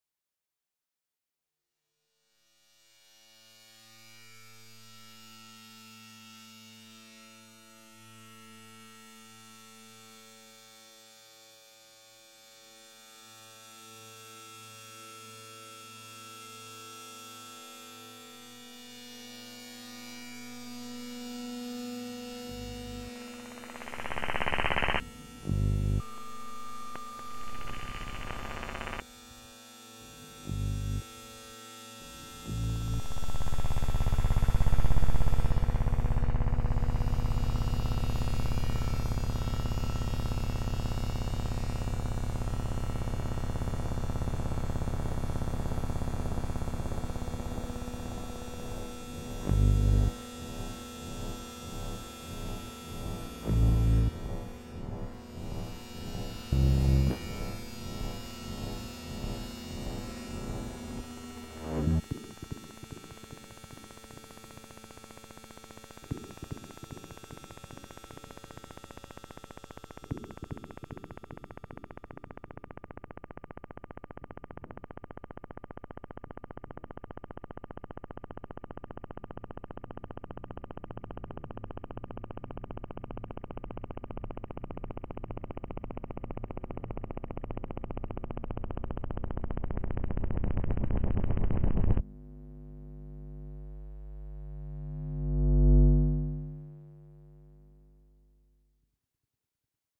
Electromagnetic sounds